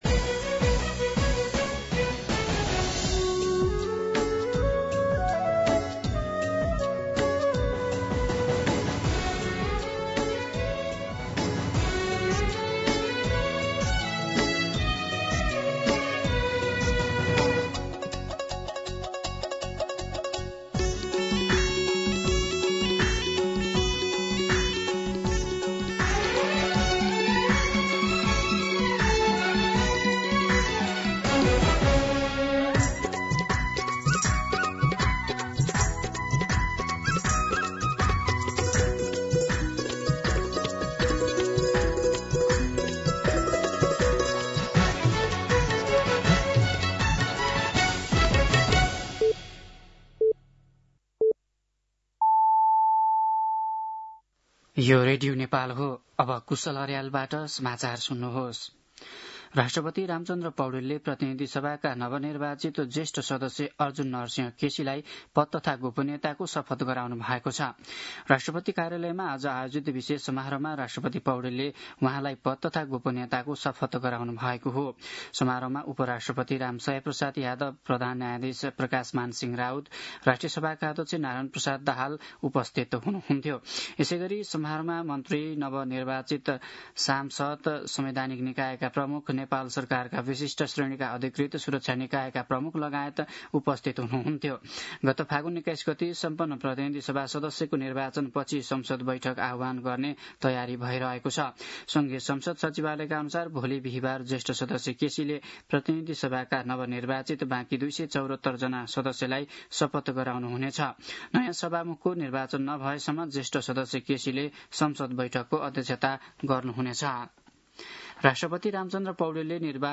दिउँसो ४ बजेको नेपाली समाचार : ११ चैत , २०८२
4pm-Nepali-News-1.mp3